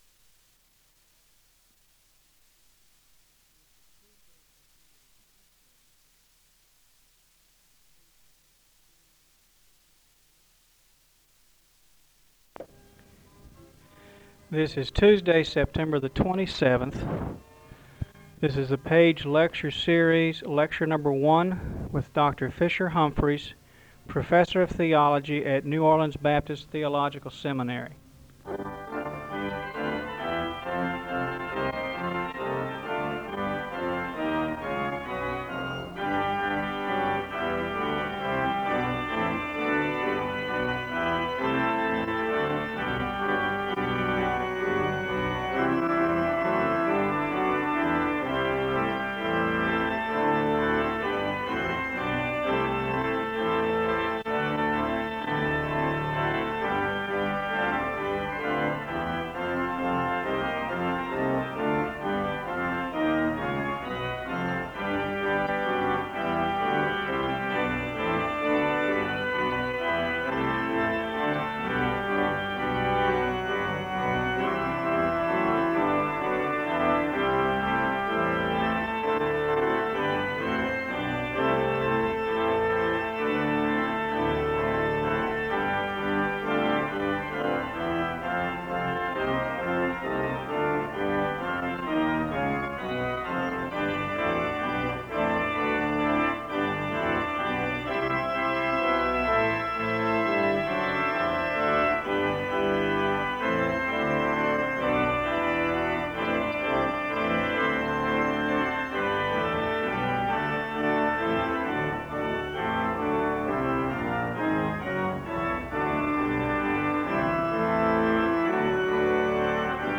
Two hymns are played (0:29-4:37).